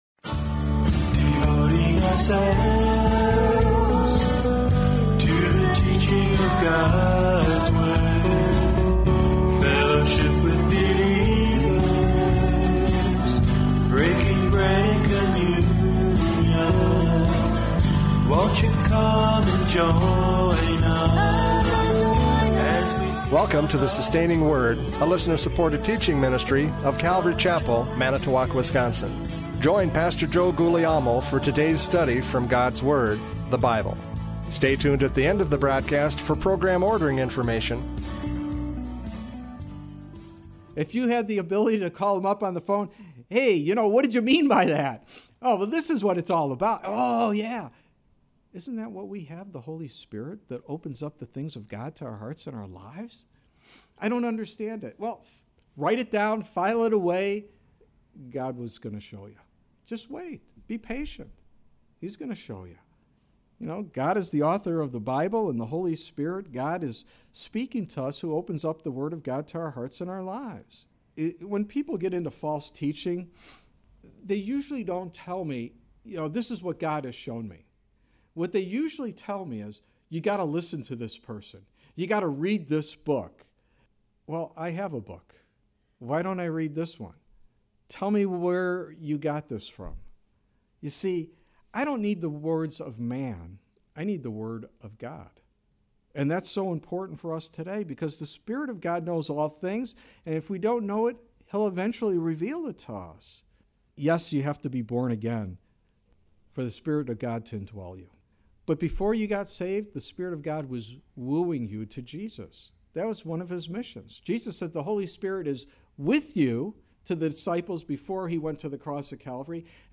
John 12:12-22 Service Type: Radio Programs « John 12:12-22 The Triumphant Entry!